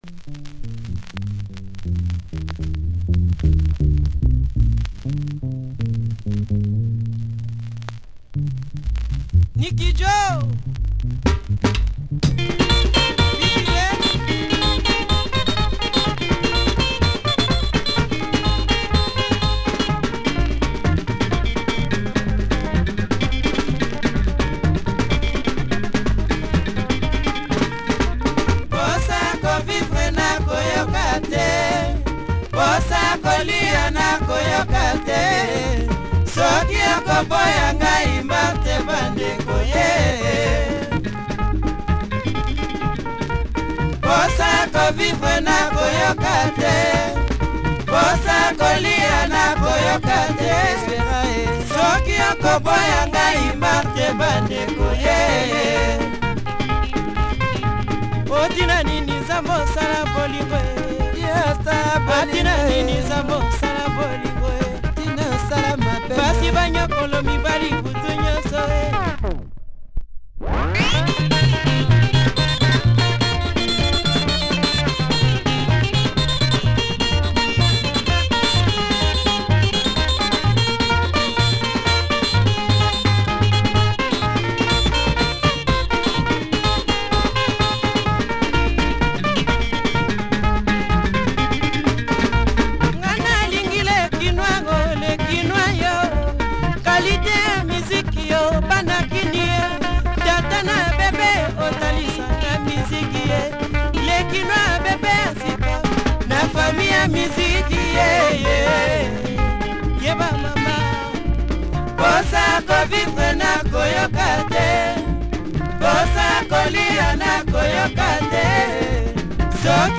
Quality Lingala dancer